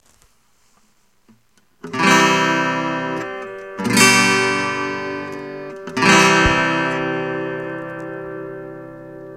１弦側のブリッジに
まずはスルーの音
直接PCへ入れた音
VOLなしの音